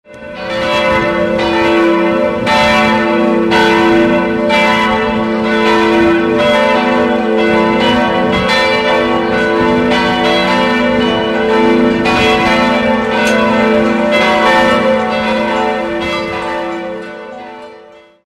Läuten der
Marienglocke
Glockengelaeut_StMarien_Gudow.mp3